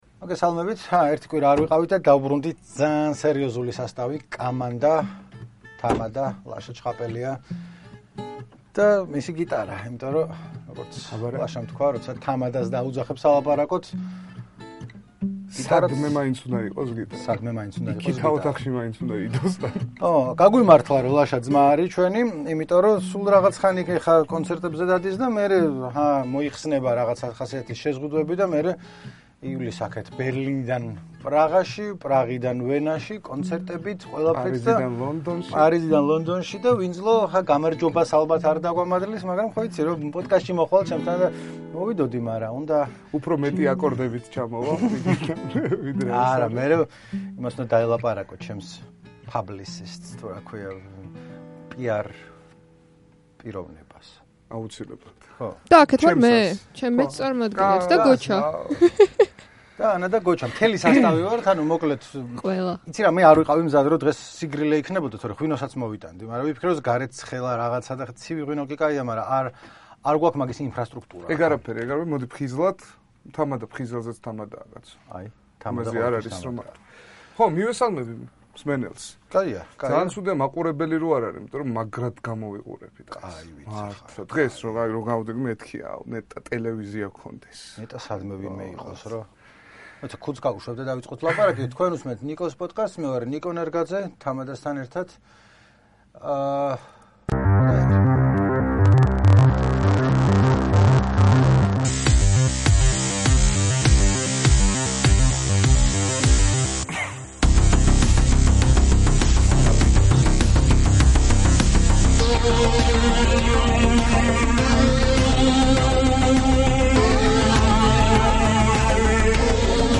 გიტარა მოიტანა, დროდადრო ხასიათის მისაცემად და ბოლოს გვიმღერა კიდეც.